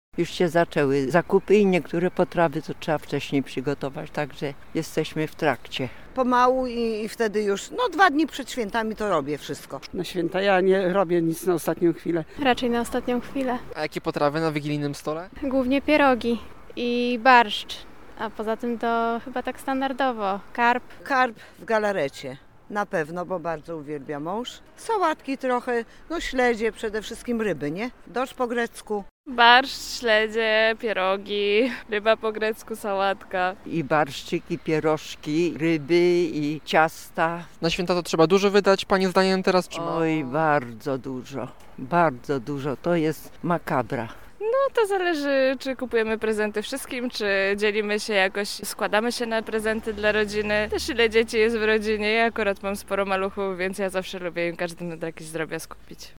sonda-swieta.mp3